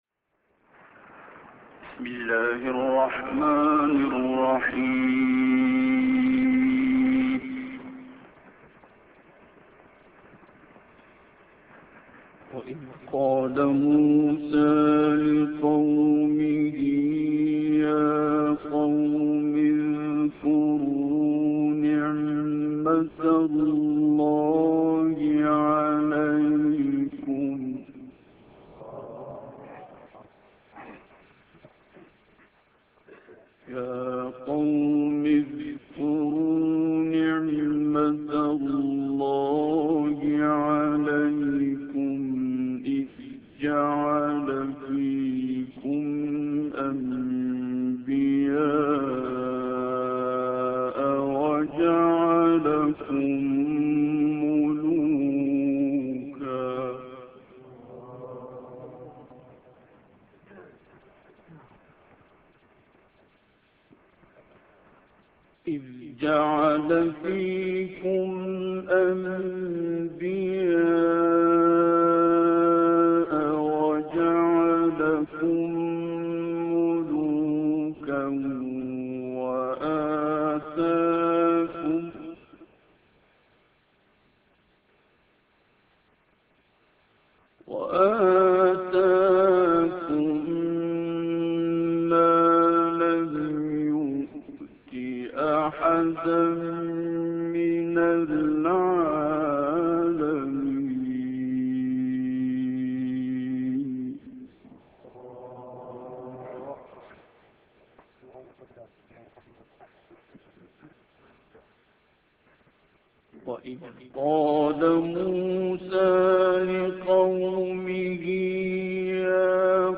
تلاوت آیاتی از سوره مائده توسط استاد عبدالباسط + متن و ترجمه